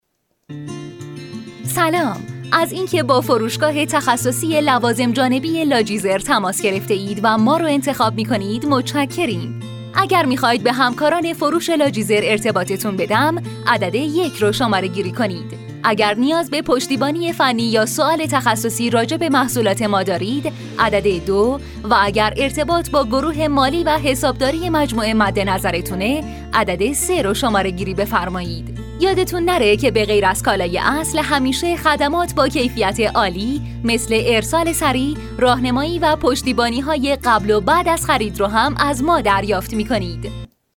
Female
Young
Adult
IVR